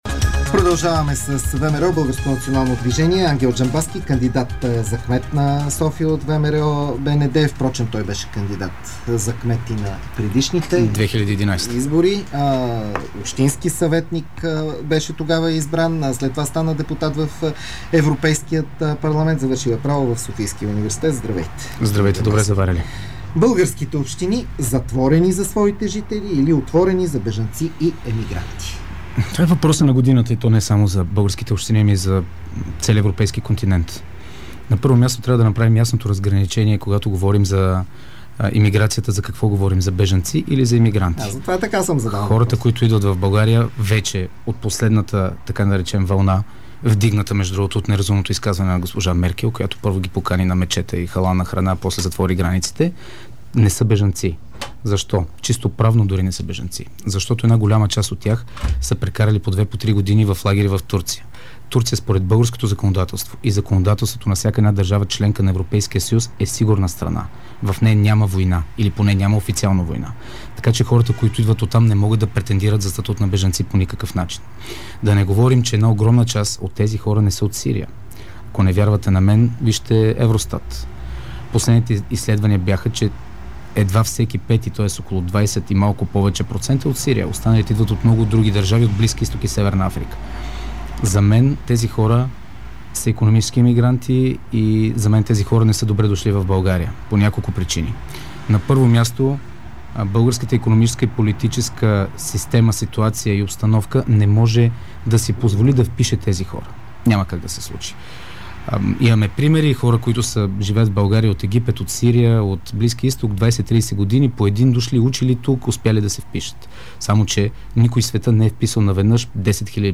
Ангел Джамбазки в студиото на Дарик